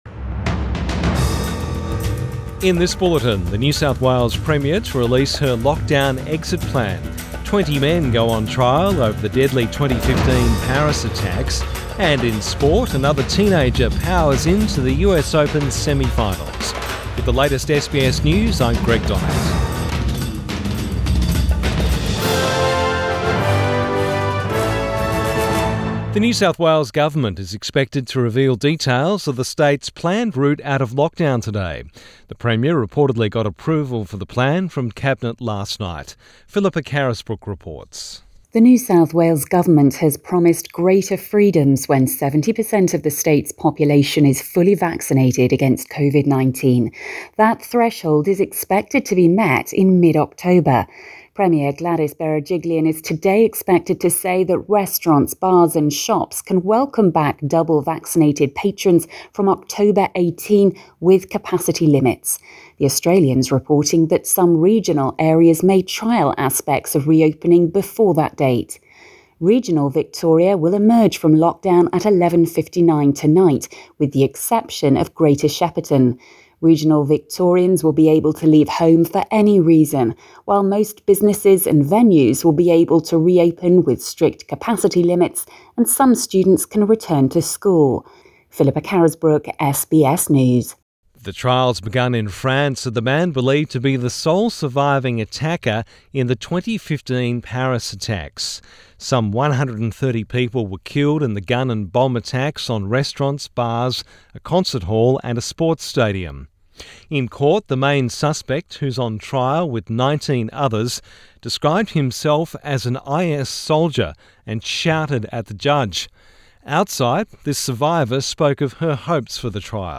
AM bulletin 9 September 2021